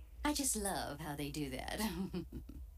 ―Odra on Icehead locomotion — (audio)